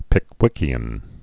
(pĭk-wĭkē-ən)